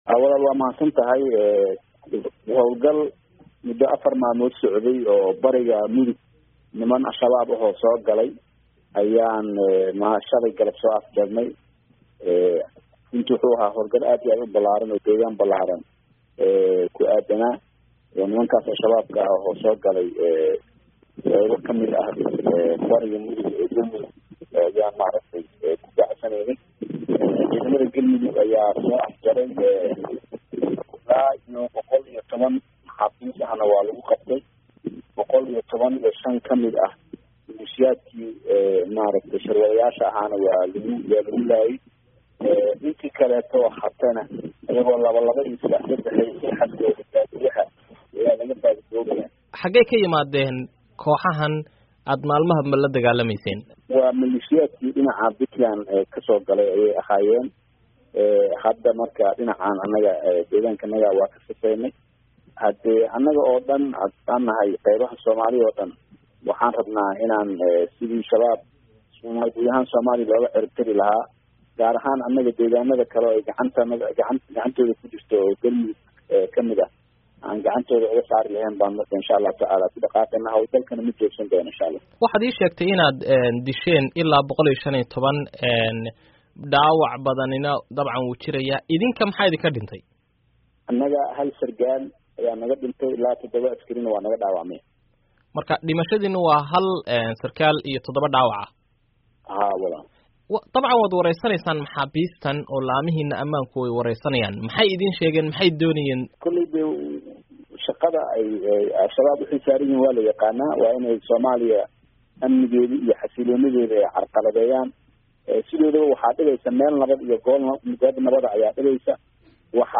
Wareysi: Madaxweynaha Galmudug